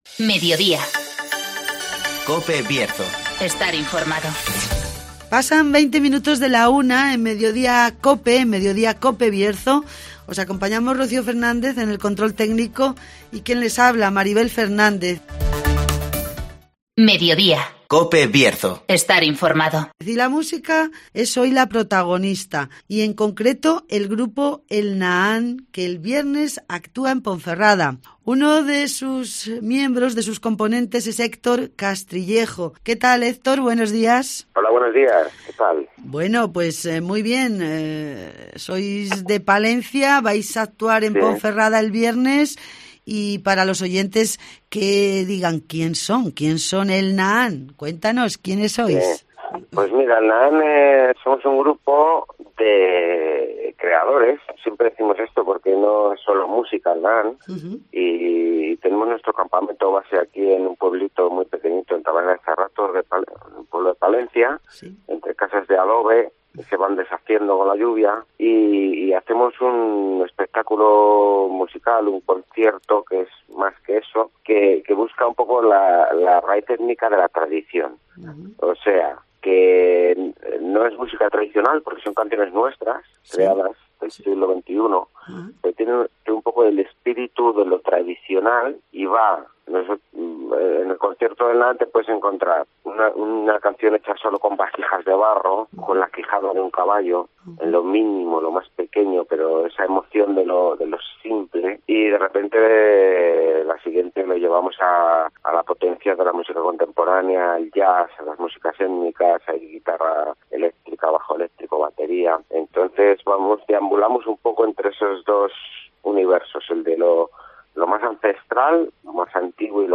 AUDIO: Repasamos la actualidad y realidad del Bierzo. Espacio comarcal de actualidad,entrevistas y entretenimiento.